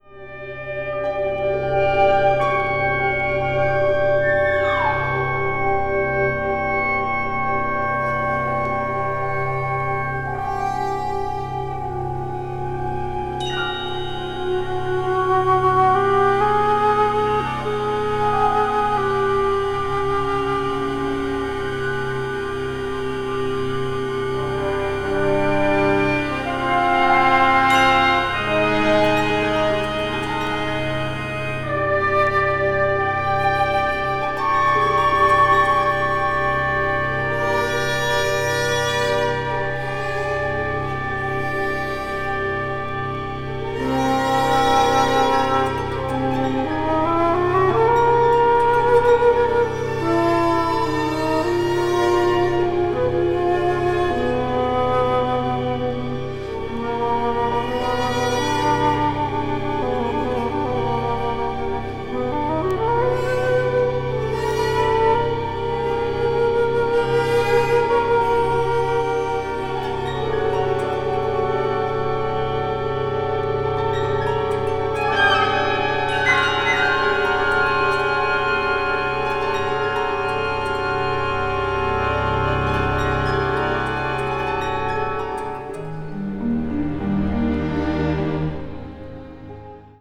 media : EX/EX(わずかにチリノイズが入る箇所あり)
中国的なモチーフがあしらわれながら大陸的なスケール感に彩られた、ミステリアスでドラマティックな世界を描き出しています。
シンセサイザーのような音も聞こえてきます。